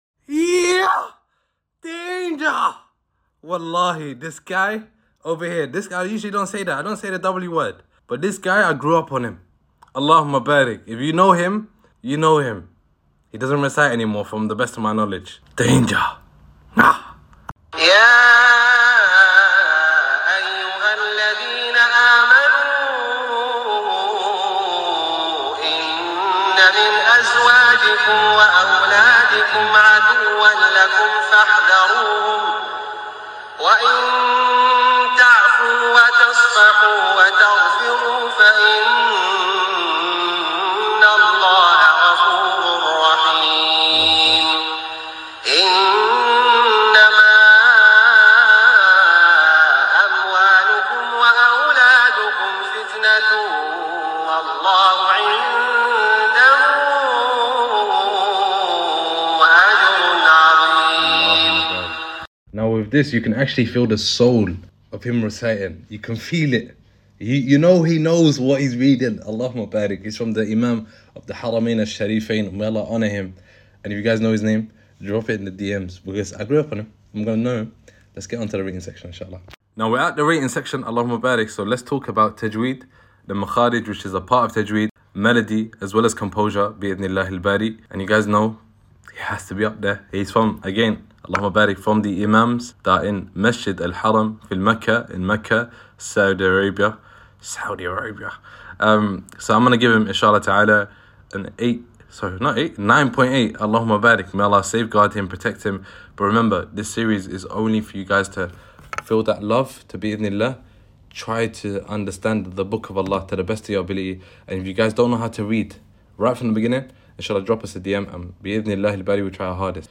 This recitation is melodious from sound effects free download